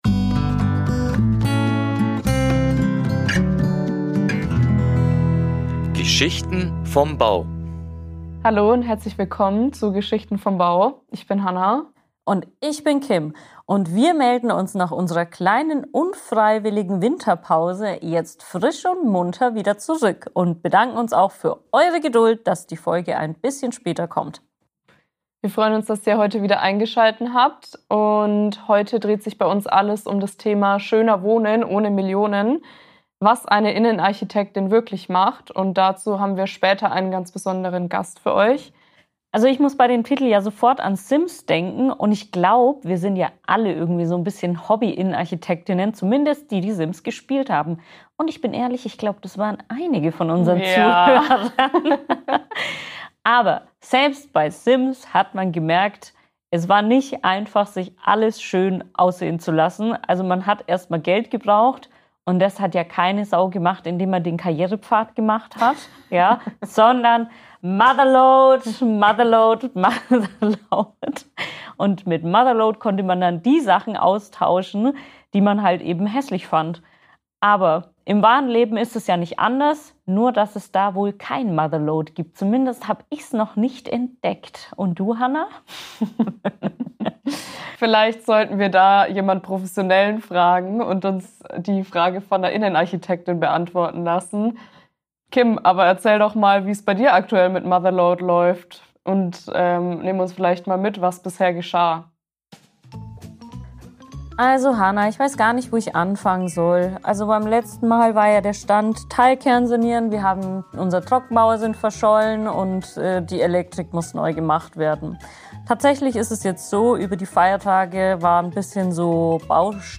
Nach einem kurzen Update von der eigenen Baustelle geht es direkt ins Gespräch mit einer Innenarchitektin. Gemeinsam sprechen sie darüber, was Innenarchitekt:innen eigentlich wirklich machen, für wen ihre Arbeit sinnvoll ist und warum gutes Design weniger mit Luxus als mit Kontext zu tun hat. Es geht um Lichtdesign, typische Gestaltungsfehler, die Pantone Color of the Year und die Frage, warum manche Ideen auf Pinterest besser funktionieren als im echten Leben.